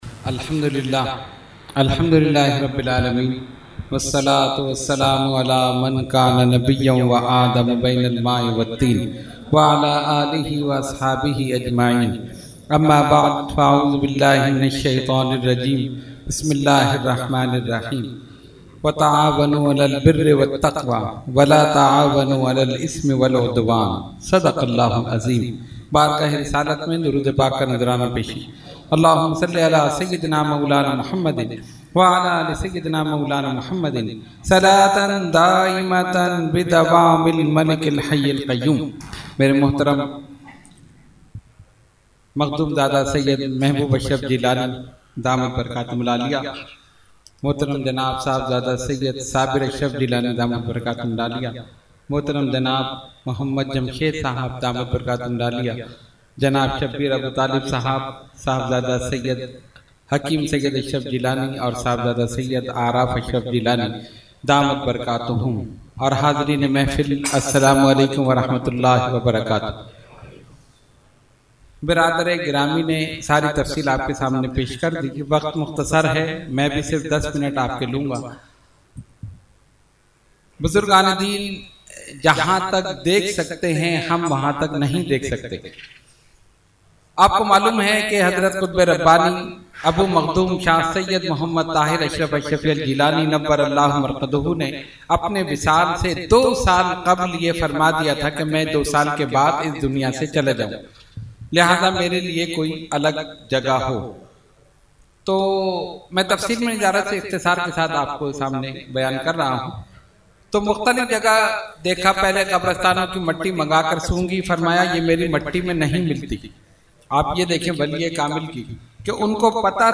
Category : Speech | Language : UrduEvent : Khatam Hizbul Bahr 2019